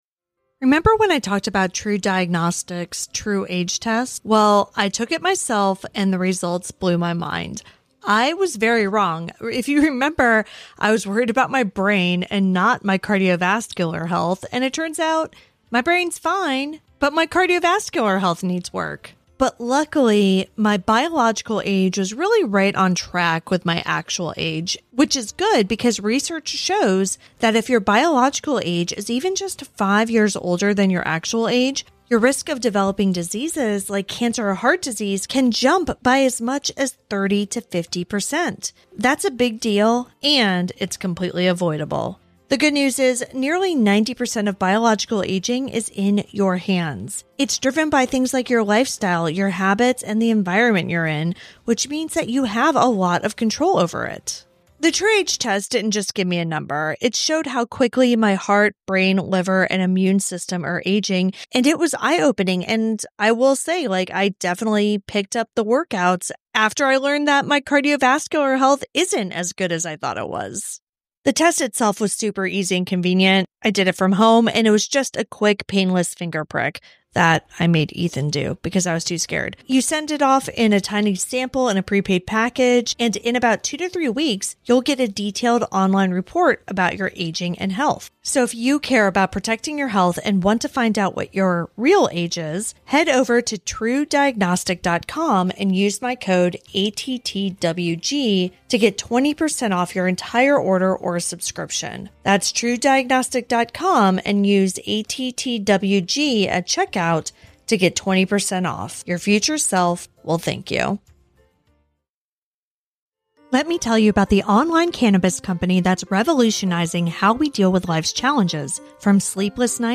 Documentary, True Crime, Society & Culture